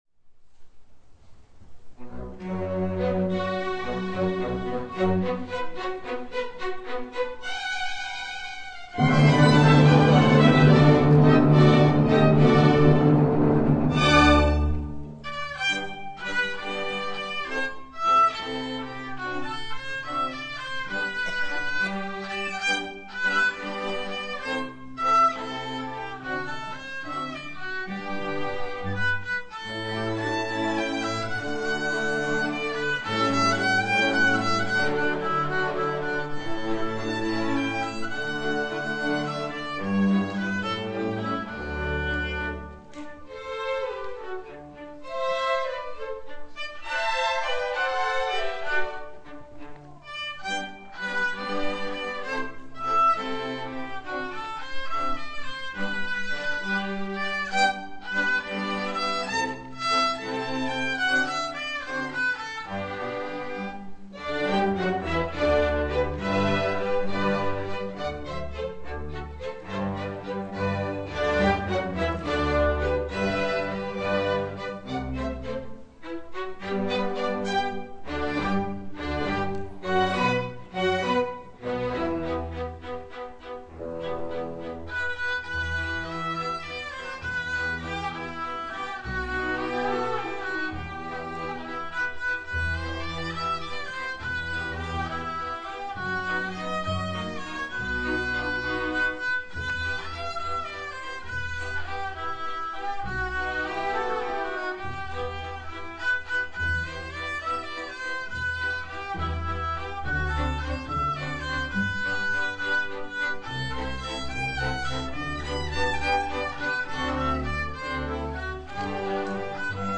F. Seitz - Koncert pro housle a orchestr pdf icon